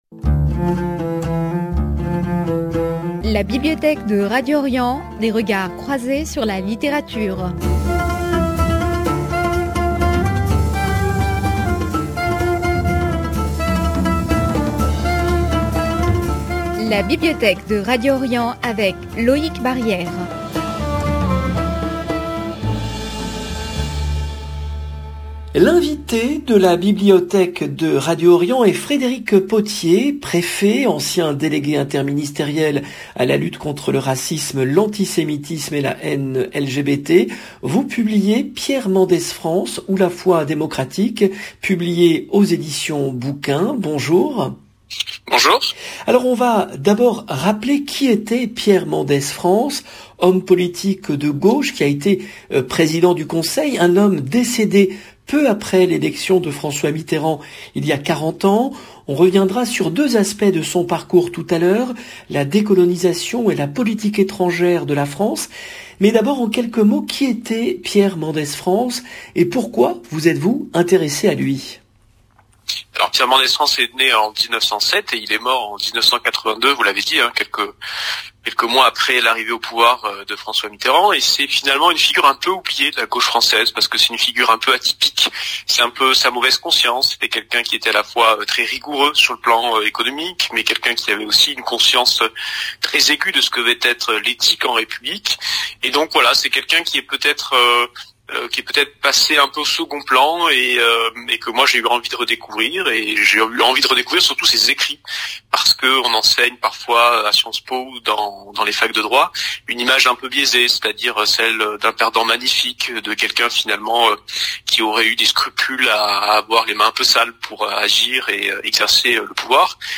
L’invité de La Bibliothèque de Radio Orient est Frédéric Potier, préfet, ancien délégué interministériel à la lutte contre le racisme, l’antisémitisme et la haine LGBT.